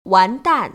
[wán//dàn] 완딴